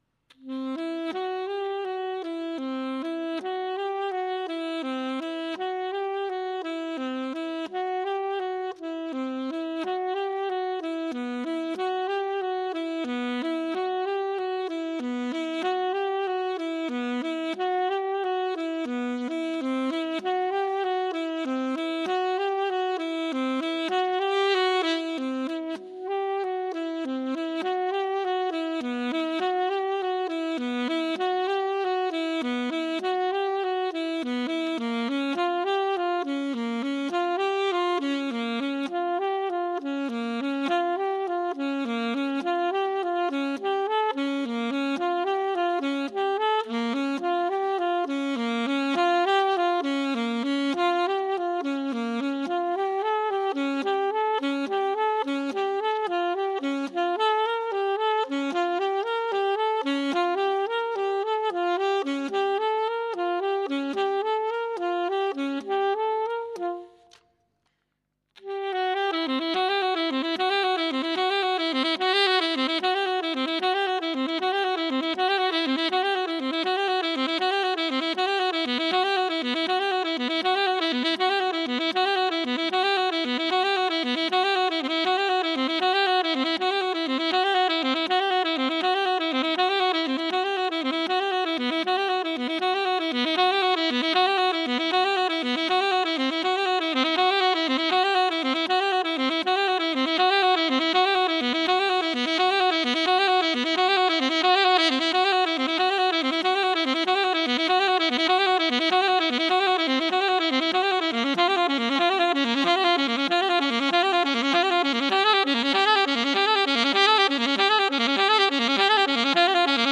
Der Solist ist zudem auf der Bühne mit sich und seinem Instrument alleine - oft einer ungeheuren Erwartungshaltung und dem eigenen schwankenden Konzentrationsvermögen ausgesetzt.